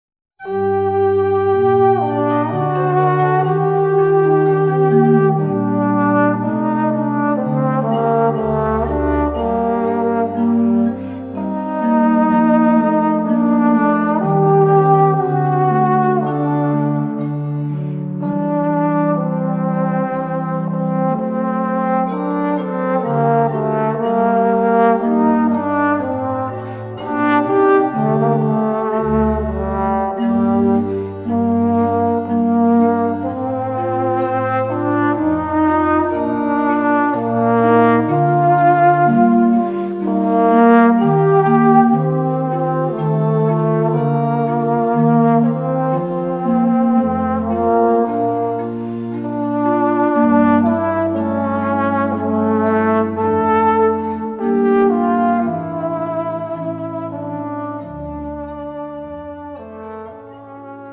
Voicing: Trombone and Organ